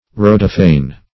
Search Result for " rhodophane" : The Collaborative International Dictionary of English v.0.48: Rhodophane \Rho"do*phane\ (r[=o]"d[-o]*f[=a]n), n. [Gr.
rhodophane.mp3